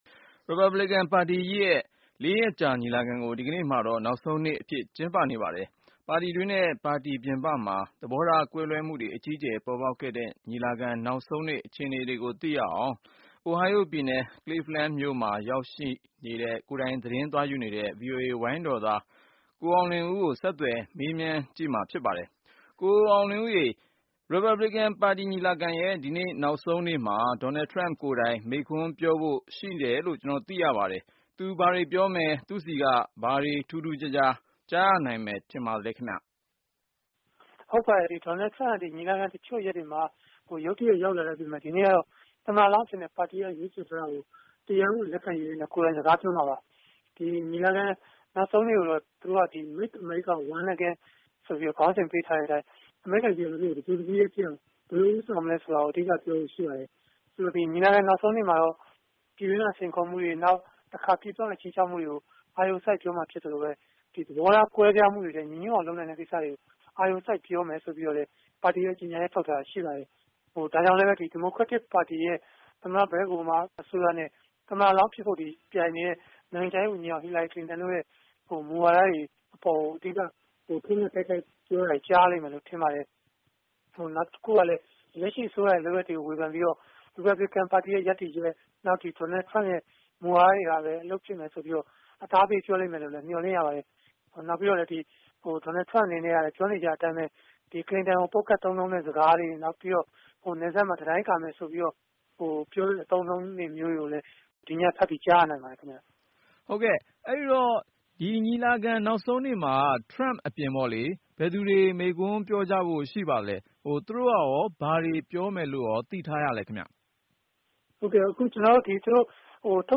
Republican ညီလာခံ တိုက်ရိုက်သတင်းပေးပို့ချက်